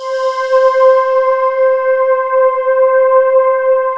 Index of /90_sSampleCDs/USB Soundscan vol.28 - Choir Acoustic & Synth [AKAI] 1CD/Partition C/08-FANTASY